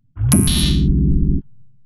UI_SFX_Pack_61_57.wav